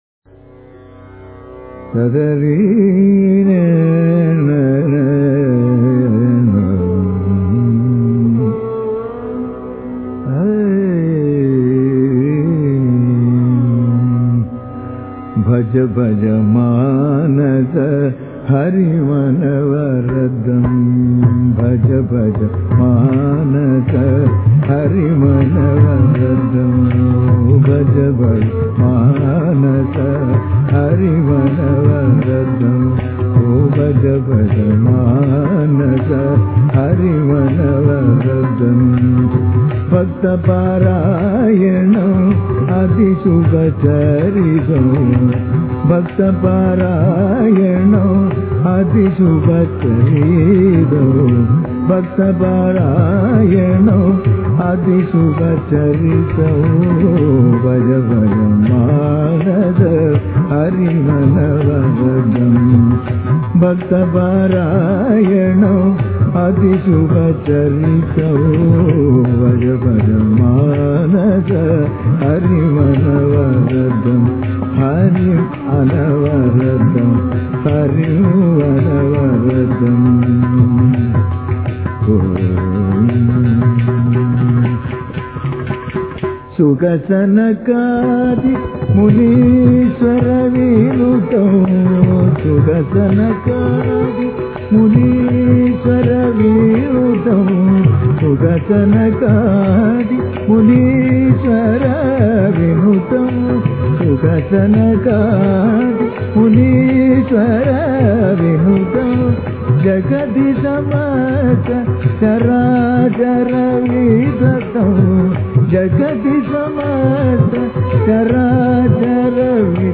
Sindhu Bhairavi; Aadi